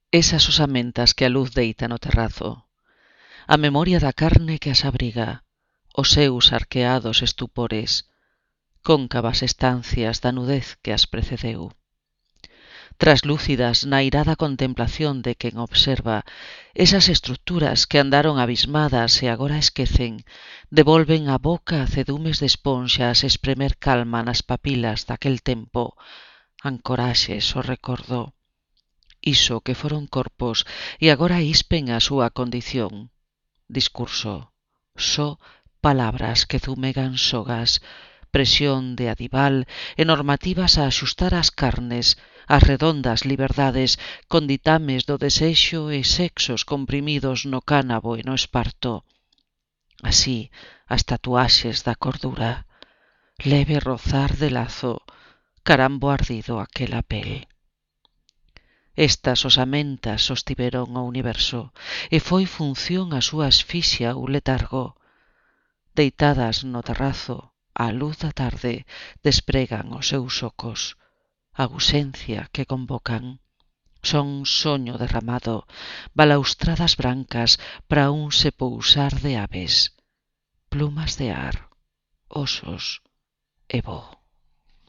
Poema recitado